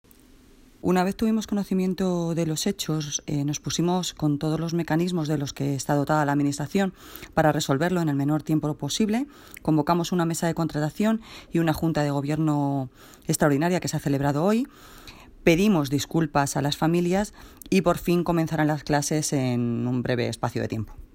Audio - Noelia Posse (Alcaldesa de Móstoles) Sobre Adjudicación Escuela Danza
Audio - Noelia Posse (Alcaldesa de Móstoles) Sobre Adjudicación Escuela Danza.mp3